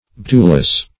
Baetulus \B[ae]"tu*lus\, n.; pl.